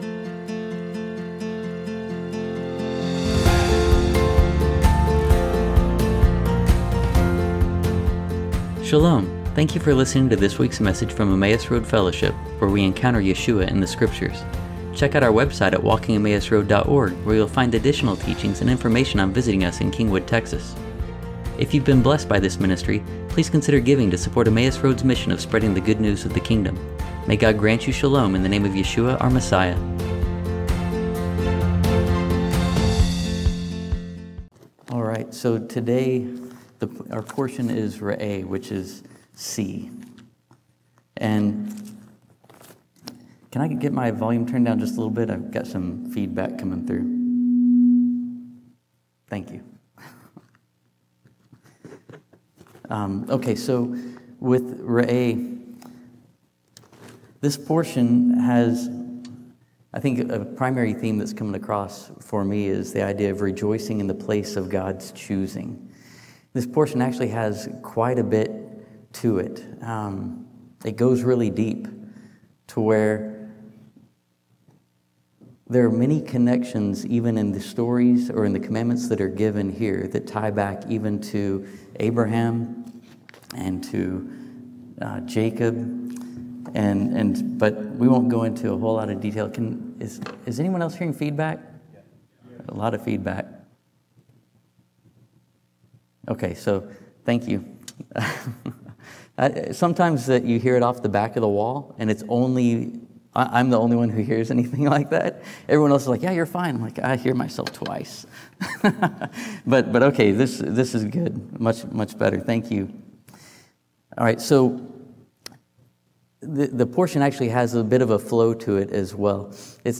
This teaching reveals how Yeshua (Jesus) becomes the ultimate place of God’s name, offering the path to life and fullness of joy through abiding in His love and keeping His commandments.